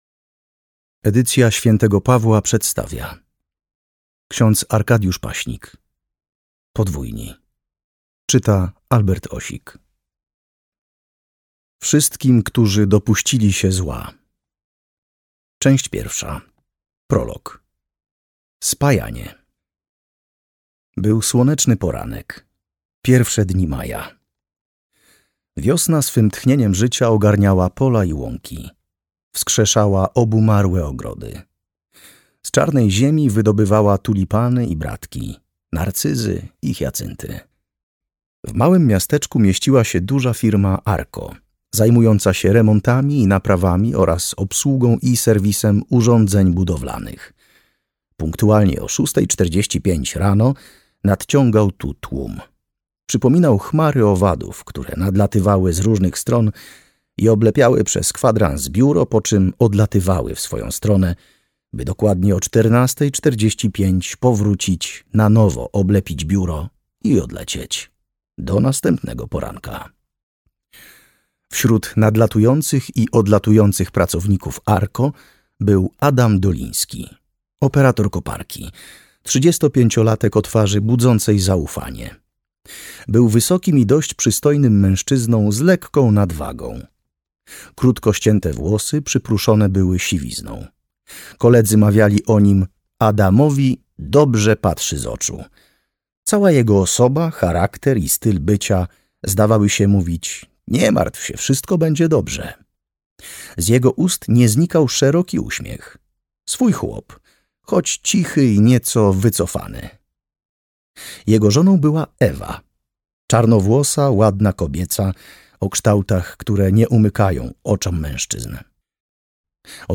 Podwójni. Plik Audiobook MP3